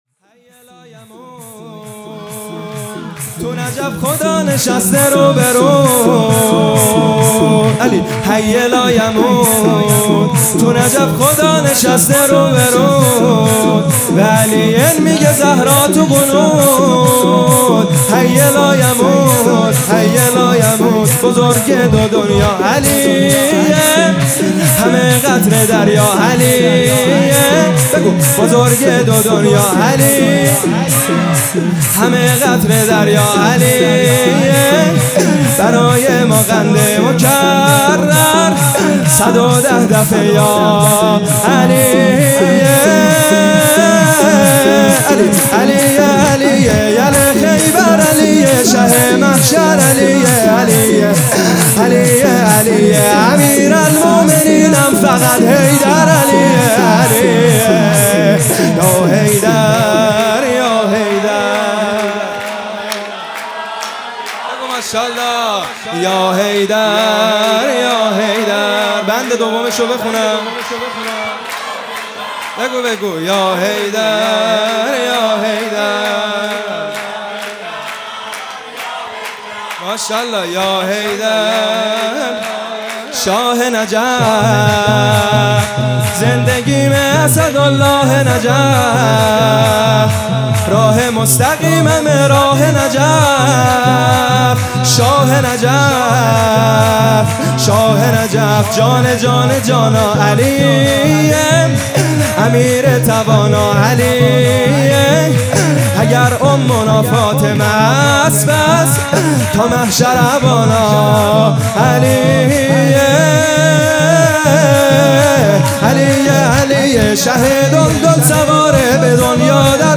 شور
شب ولادت امیرالمؤمنین حضرت علی